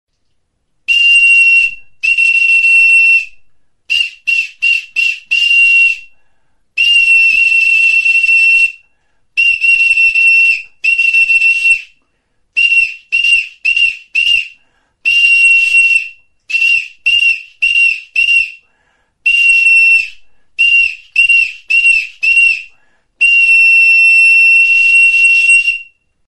TXULUBITA; LATORRIZKO TXULUBITA; TXIFLOA | Soinuenea Herri Musikaren Txokoa
Enregistré avec cet instrument de musique.
Aérophones -> Flûtes -> Á Bec (á une main)
Description: Latorrizko txaparekin egindako txifloa edo zulorik gabeko flauta sinplea da.